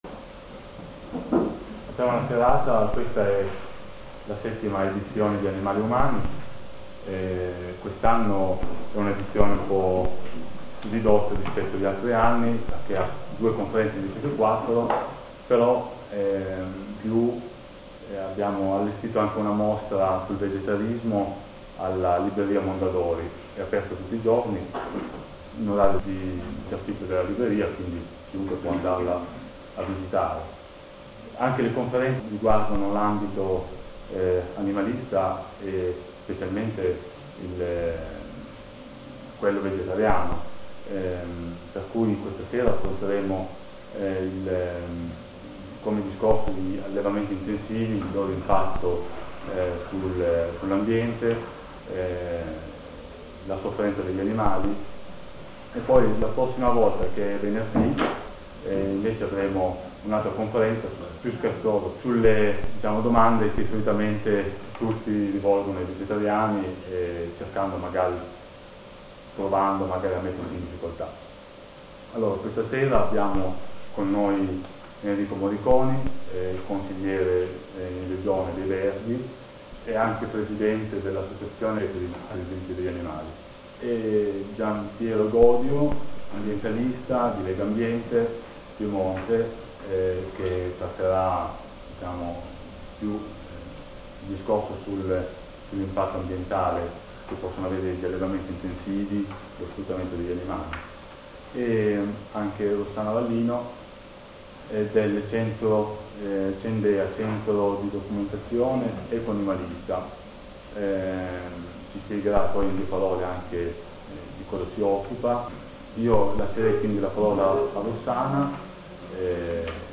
Ciclo di conferenze: ''Animali e Umani'' 7, novembre 2007 - AgireOra Alessandria